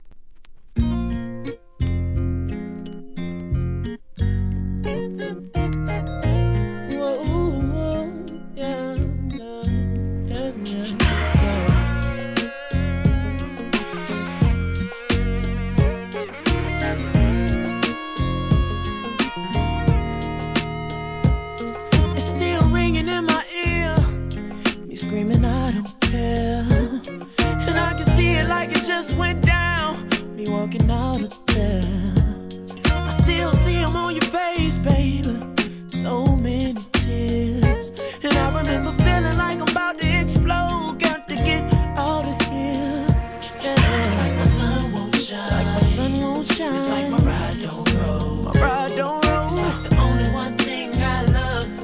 HIP HOP/R&B
和やかな味わいあるヴォーカルは正統派R&Bシンガー!!!